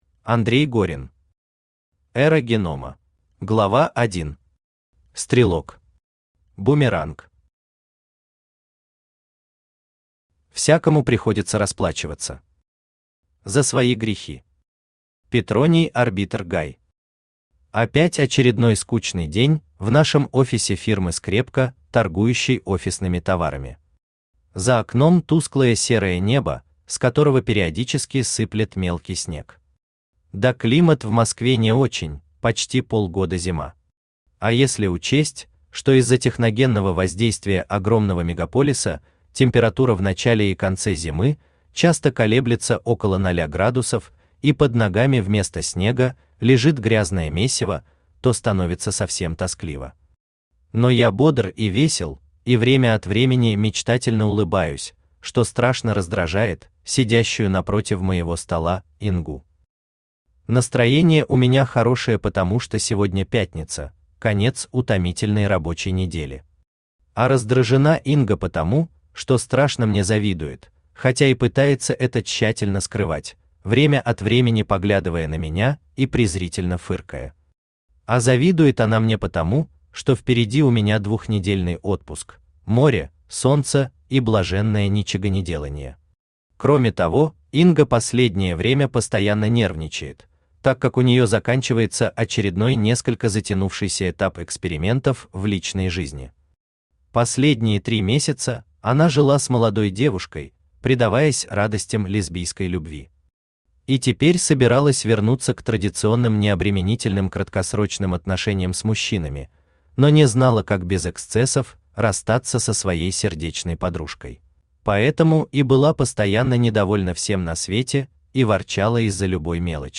Aудиокнига Эра Генома Автор Андрей Горин Читает аудиокнигу Авточтец ЛитРес.